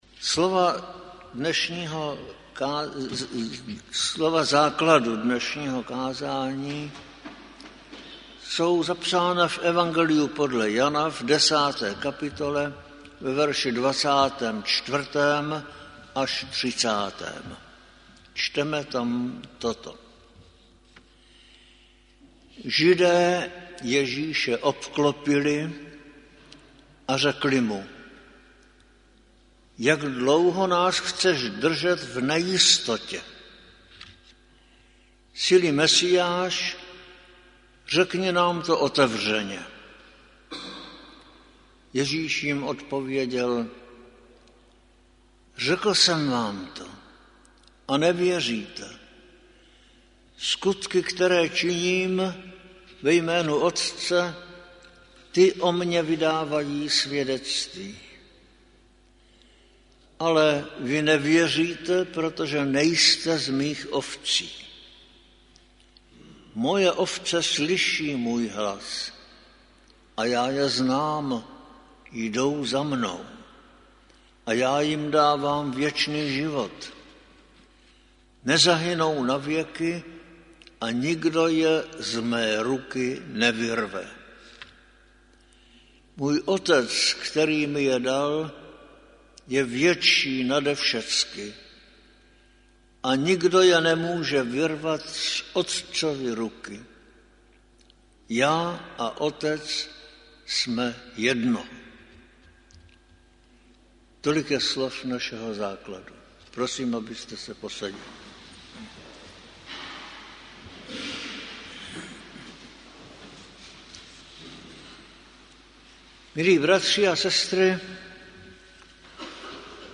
Záznam kázání a sborová ohlášení.